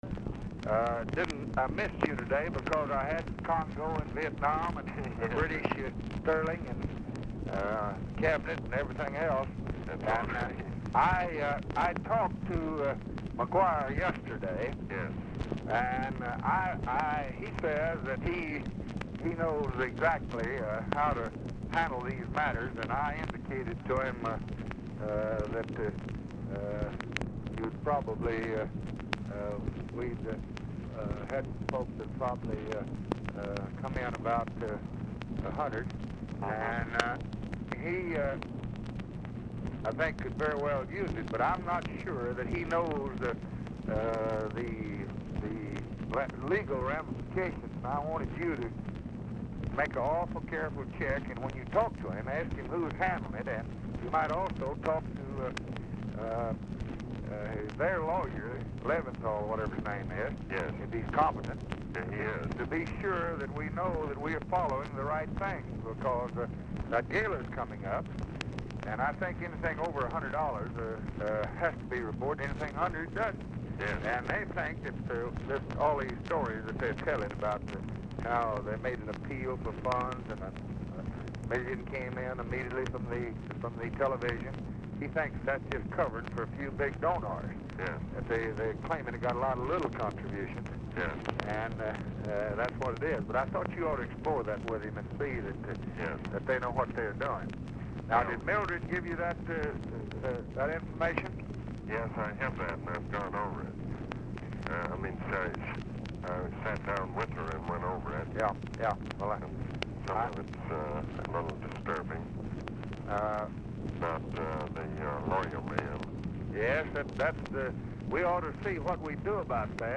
RECORDING STARTS AFTER CONVERSATION HAS BEGUN
POOR SOUND QUALITY
Format Dictation belt
Specific Item Type Telephone conversation